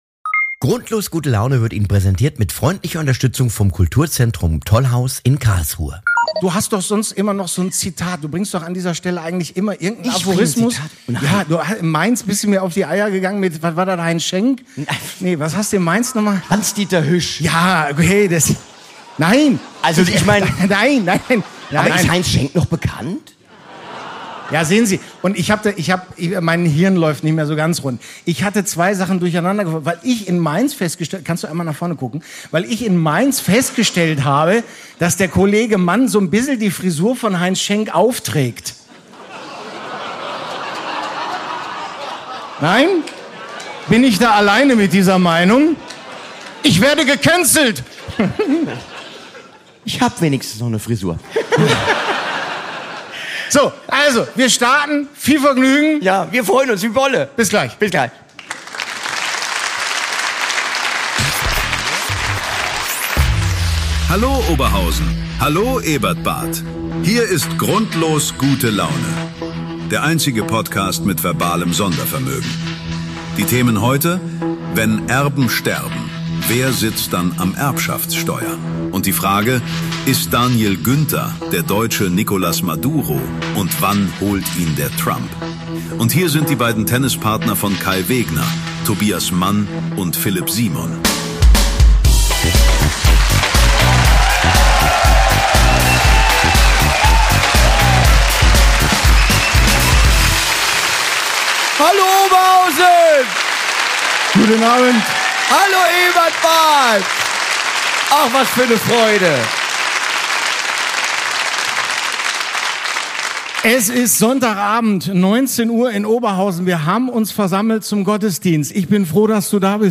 Grundlos Gute Laune live aus dem Ebertbad in Oberhausen!